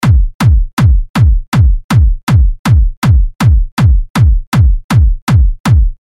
舞蹈踢腿160Bpm
标签： 160 bpm Dance Loops Drum Loops 1.01 MB wav Key : Unknown
声道立体声